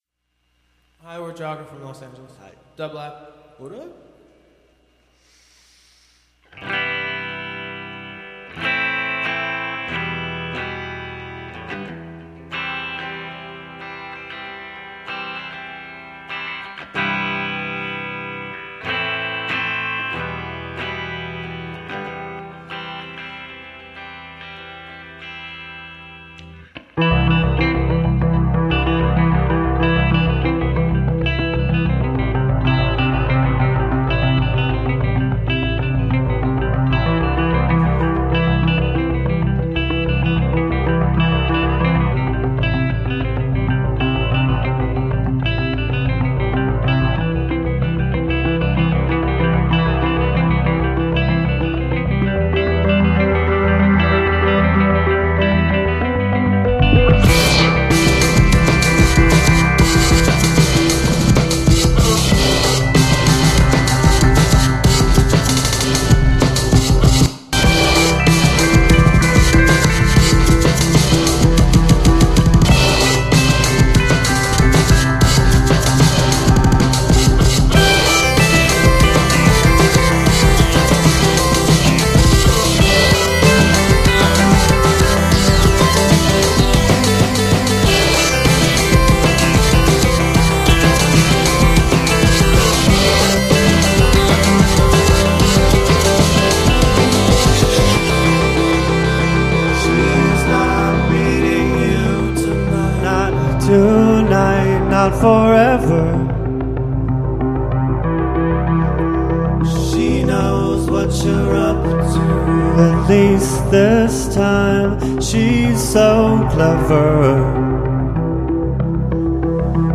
power-laden jams
Electronic Pop Synth